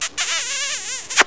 KISS_UP.WAV